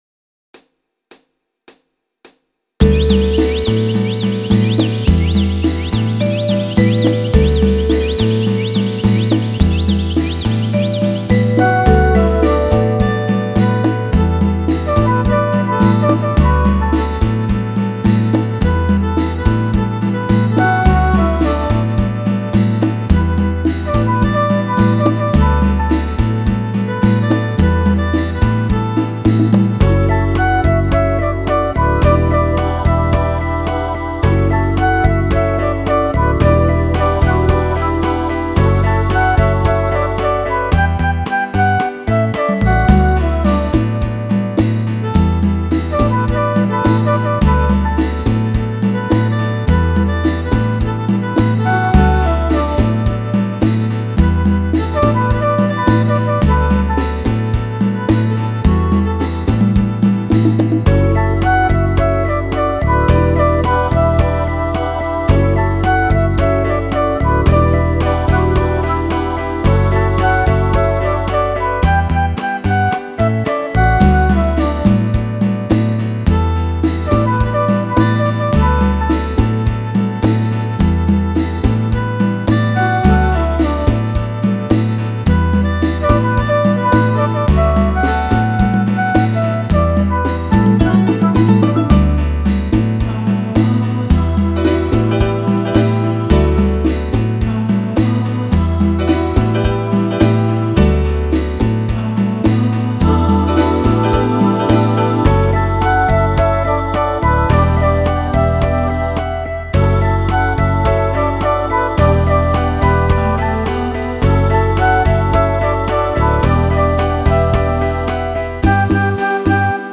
thigvls72t  Download Instrumental
If there was ever a “laid back” song, this is it.